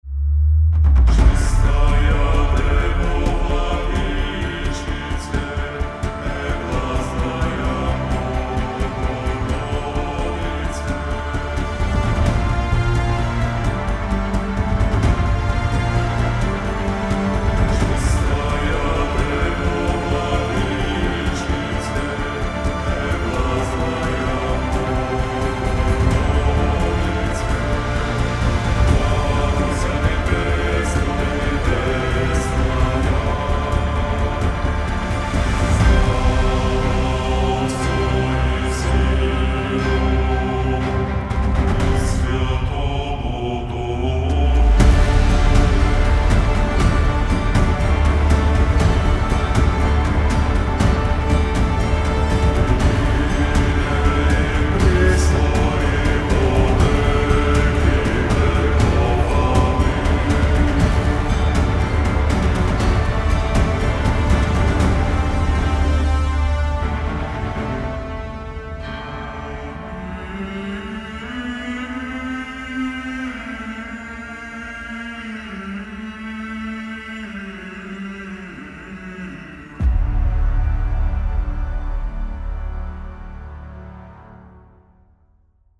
此外，这个库还包含了一个“嗯”音的持续音和呼吸音，可以用来增加真实感。